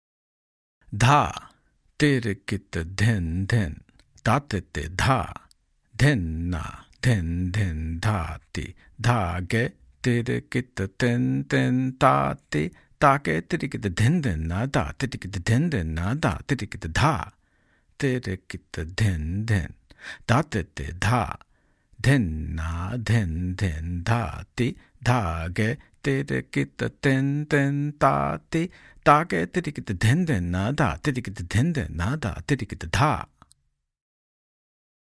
In this section and the next, there are practice variations, accompaniment variations, and theka improvisations in vilambit and madhyalay tempos (slow and medium):
• Accompaniment variations present embellishments and fills which are more typical of accompaniment and are not as repetitive.